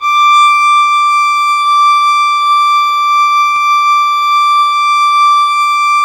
Index of /90_sSampleCDs/Roland - String Master Series/STR_Violin 4 nv/STR_Vln4 no vib
STR VLN BO0L.wav